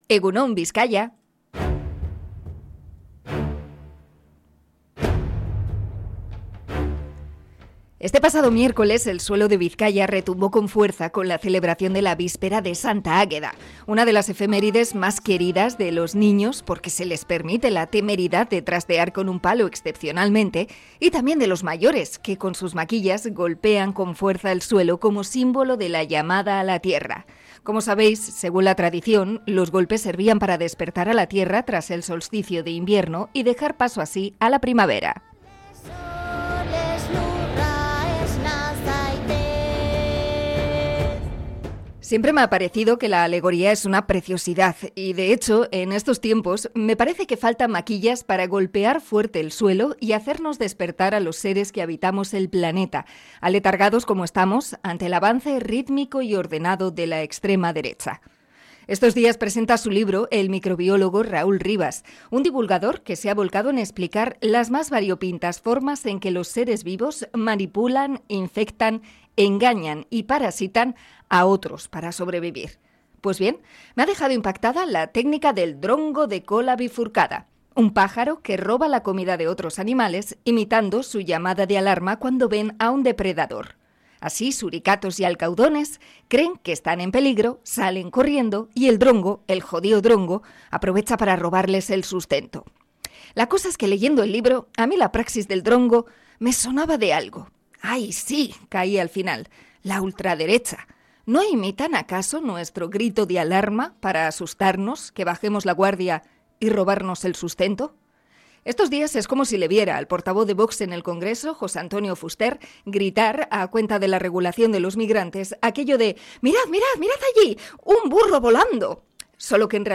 Comentario sobre la urgencia de despertar ante la extrema derecha